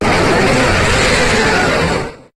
Cri de Zygarde dans sa forme Parfaite dans Pokémon HOME.
Cri_0718_Parfaite_HOME.ogg